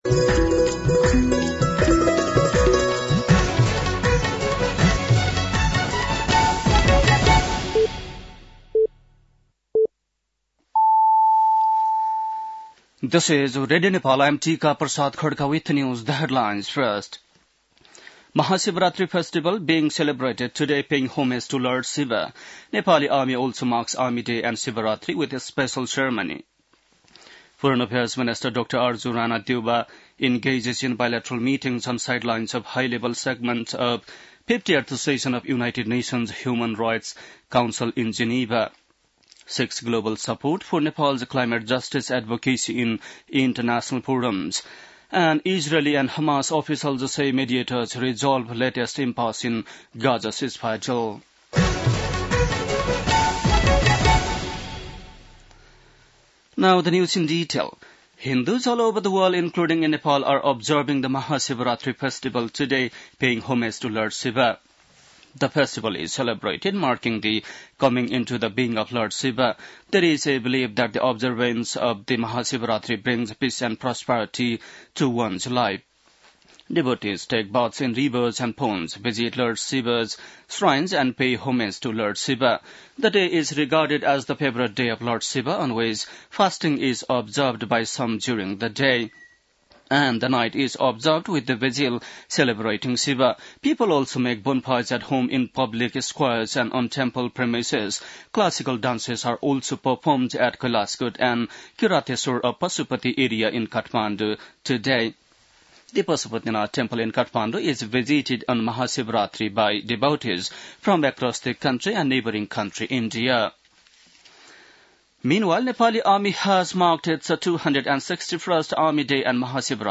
बेलुकी ८ बजेको अङ्ग्रेजी समाचार : १५ फागुन , २०८१